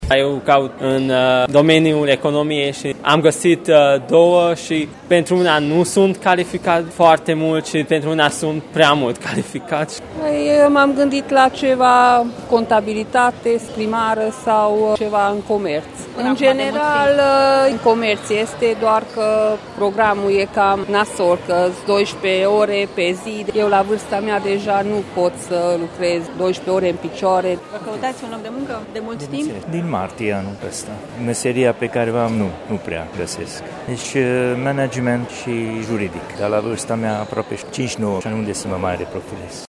Agenția Județeană pentru Ocuparea Forței de Muncă organizează astăzi, la Sala Polivalentă din Târgu Mureș, Bursa Locurilor de Muncă pentru absolvenți, la care pot participa toți cei aflați în căutarea unui loc de muncă.
Și cei fără ocupație au dificultăți să găsească un loc de muncă la Târgu Mureș: